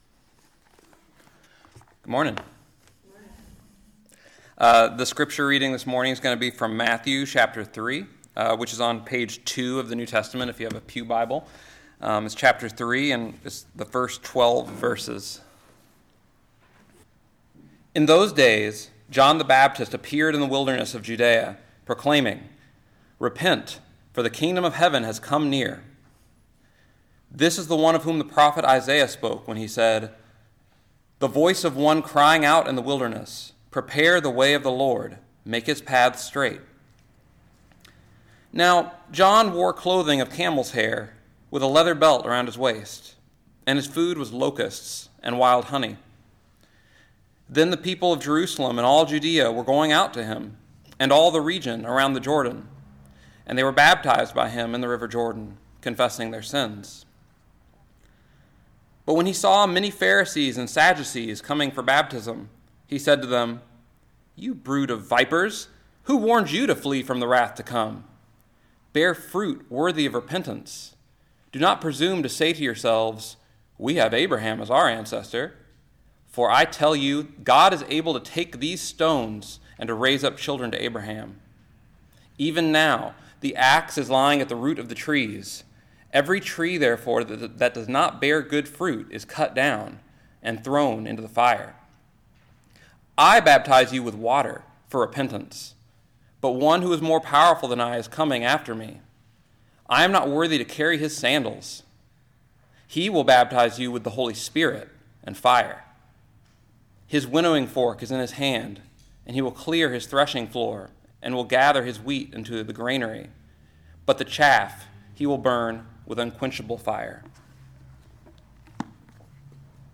Listen to the most recent message from Sunday worship at Berkeley Friends Church, “From These Stones.”